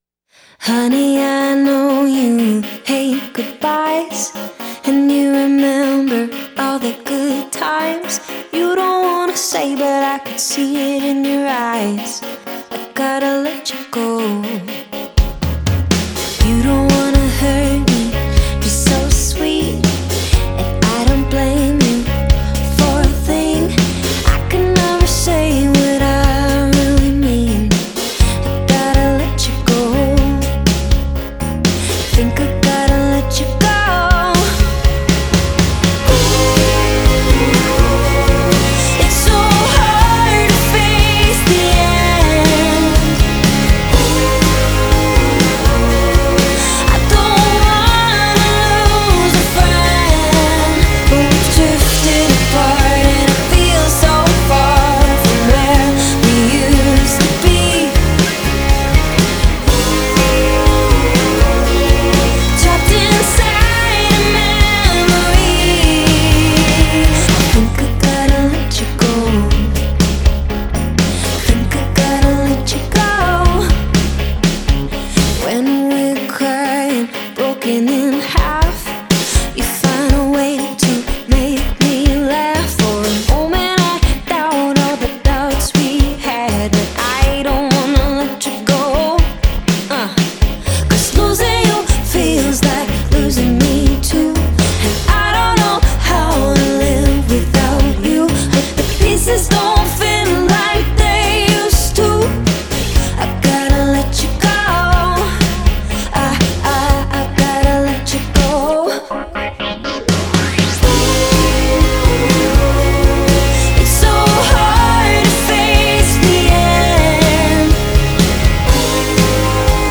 Genre: Indie Pop, Alternative